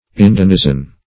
Search Result for " indenizen" : The Collaborative International Dictionary of English v.0.48: Indenizen \In*den"i*zen\, v. t. [imp.